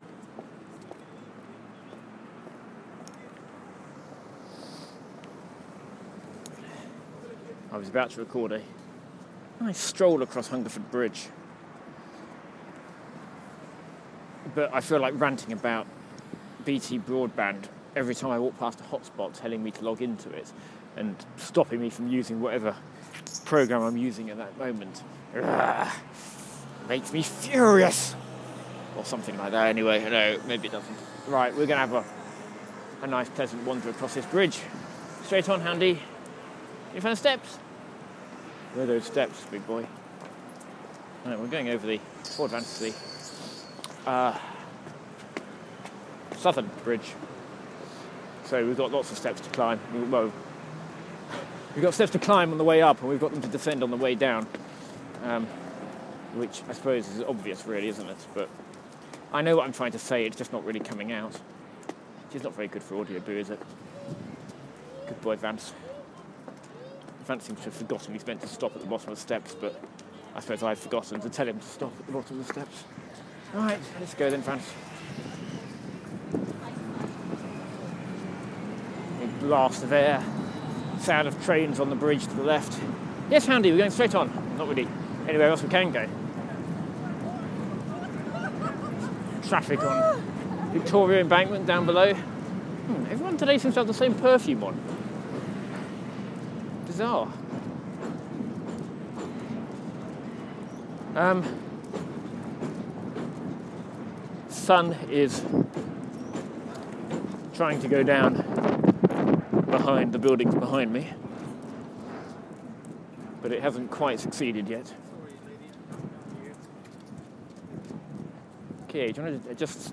Walking from Northumberland Avenue to Waterloo Station
I walk across Hungerford Bridge, crossing the Thames from Victoria embankment to the Southbank.